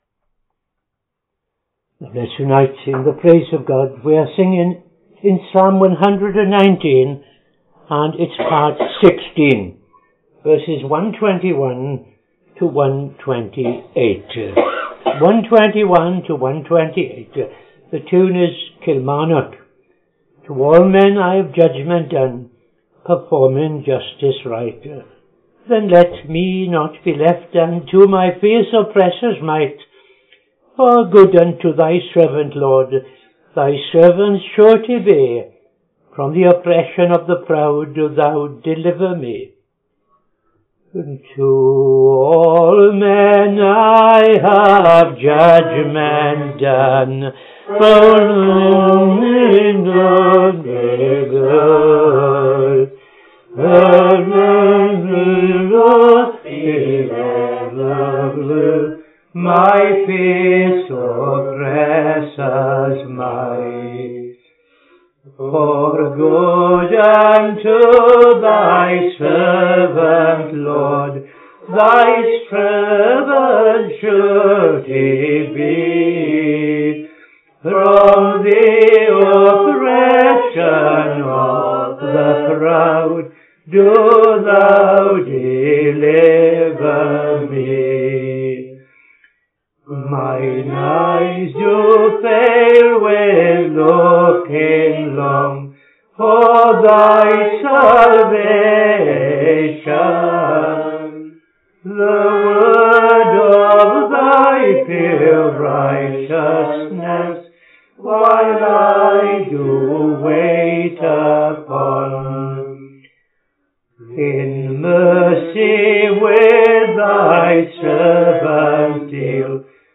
Evening Service - TFCChurch
5.00 pm Evening Service Opening Prayer and O.T. Reading I Chronicles 14:1-17